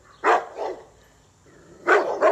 bark
Tags: duck